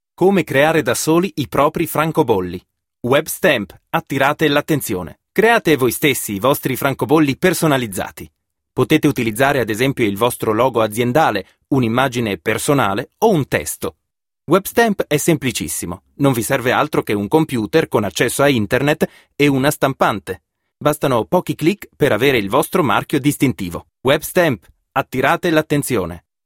Sprecher Italienischer Muttersprache in der Schweiz.
Sprechprobe: eLearning (Muttersprache):